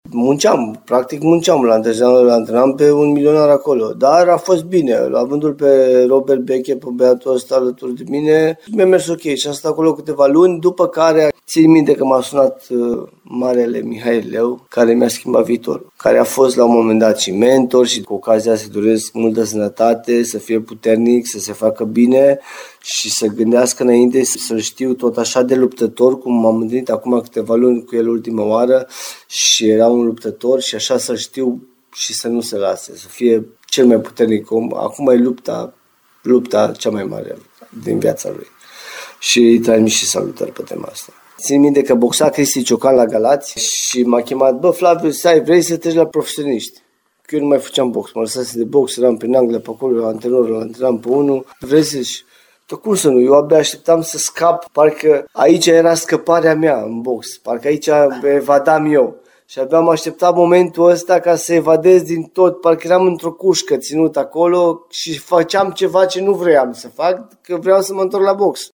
interviului